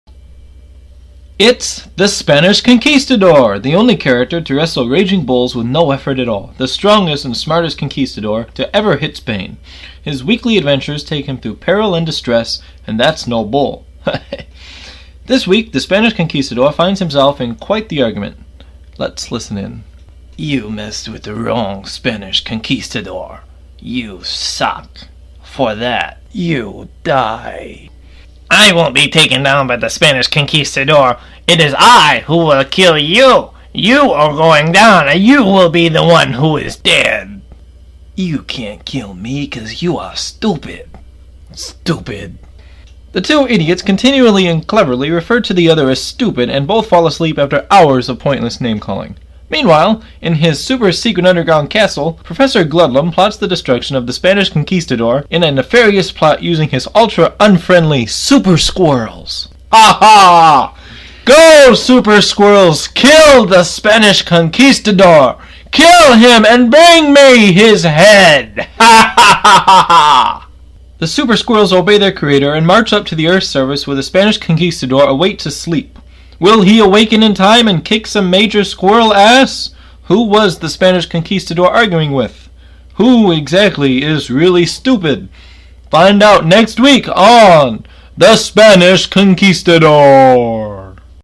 The Spanish Conquistador is an ongoing audio comedy series presented by Wayward Orange.